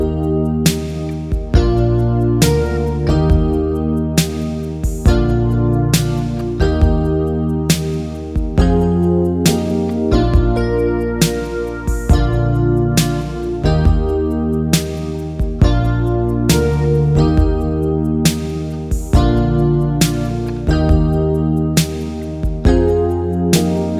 No Backing Vocals Pop (1980s) 4:02 Buy £1.50